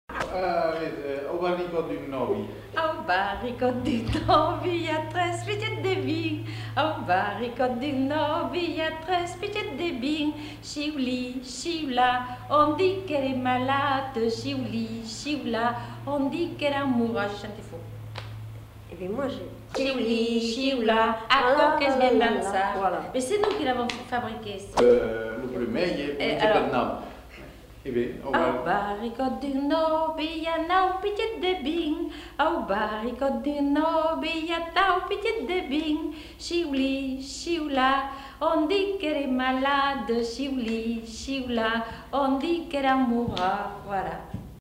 Aire culturelle : Réolais
Lieu : Lamothe-Landerron
Genre : chant
Effectif : 1
Type de voix : voix de femme
Production du son : chanté
Classification : danses